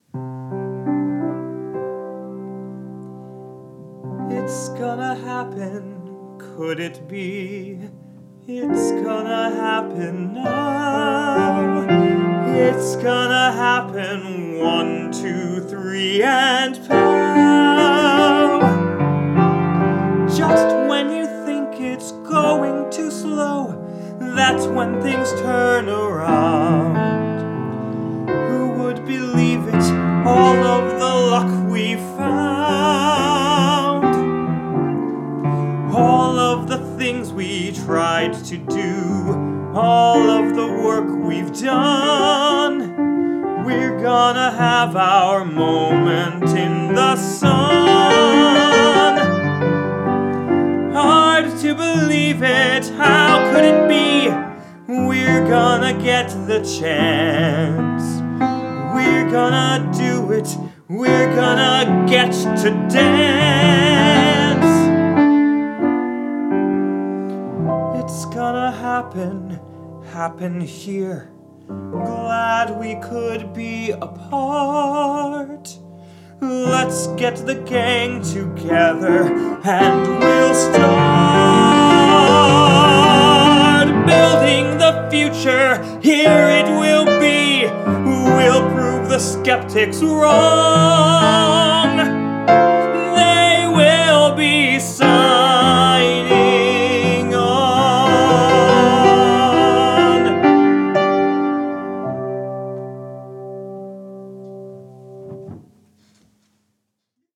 Note: The following includes charts, context, and rough demos (some rougher than others).
(scene 5) It’s Gonna Happen (solo, duet, ensemble):